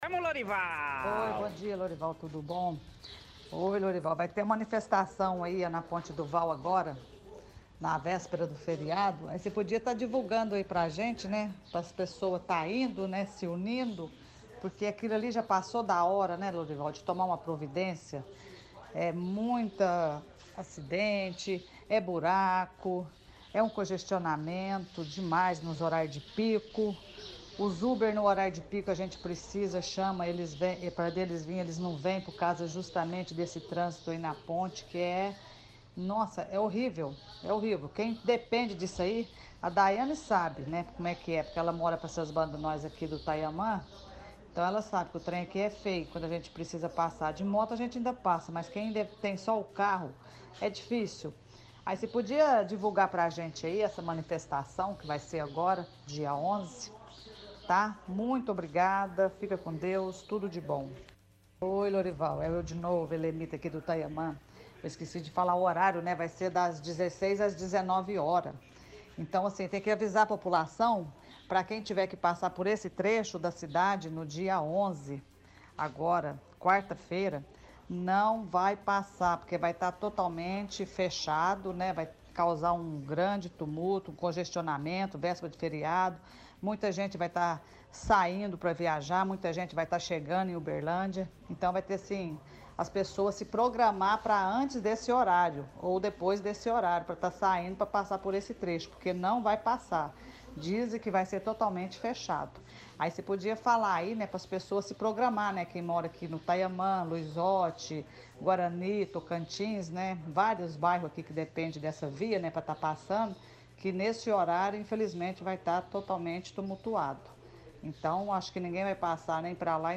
– Ouvinte do bairro Taiaman diz que terá manifestação na ponte do Vau que vai acontecer dia 11 por conta do abandono do poder público em relação ao trânsito naquela região.